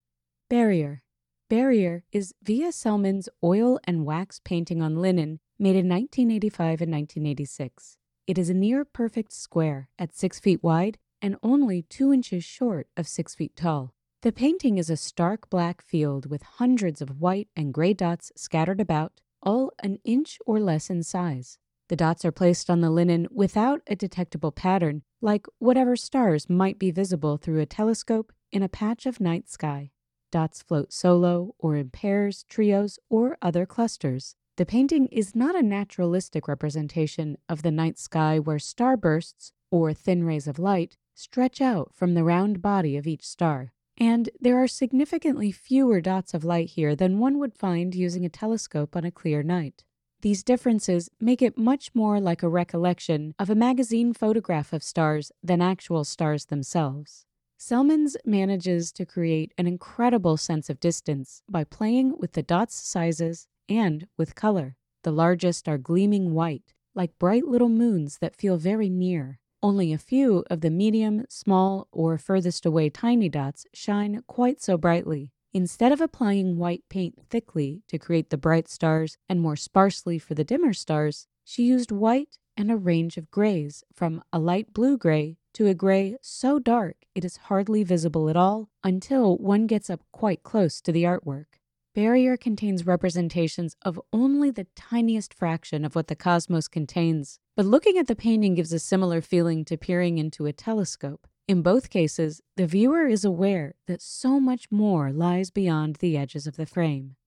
Audio Description (01:47)